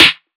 SNARE 3.wav